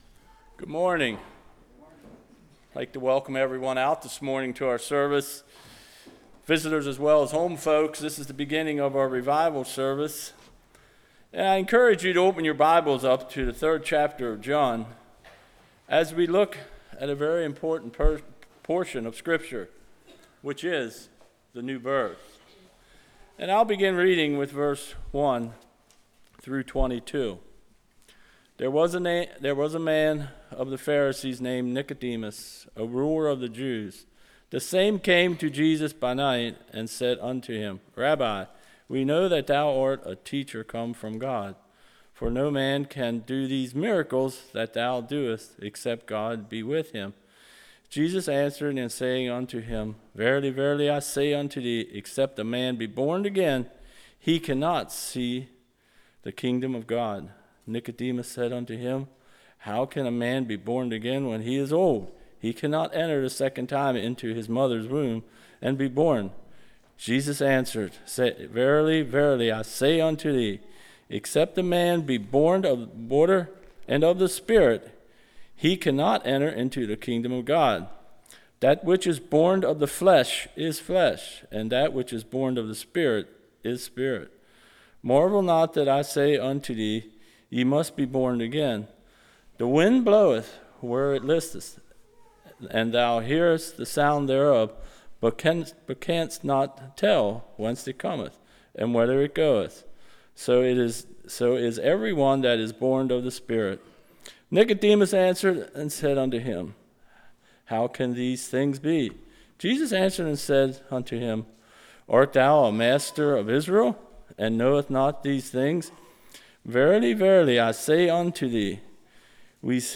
Series: Fall Revival 2018
Service Type: Sunday School